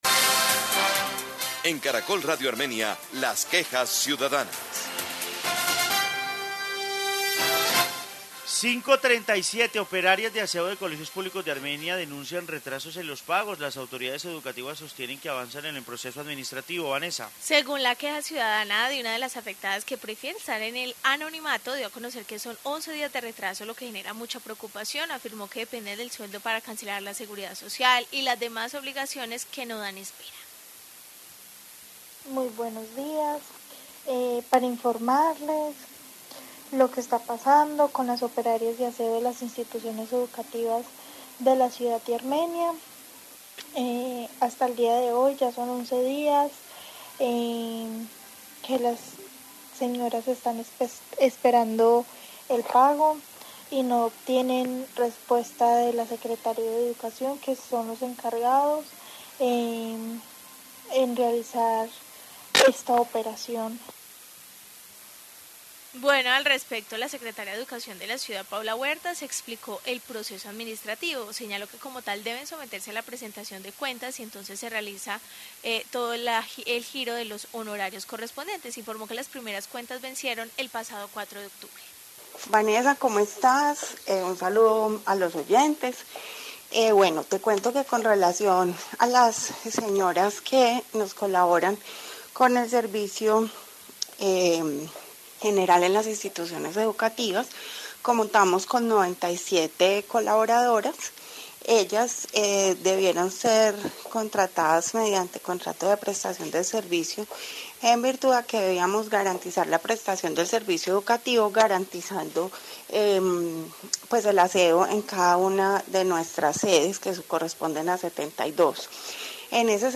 Informe sobre queja de operarias de aseo y respuesta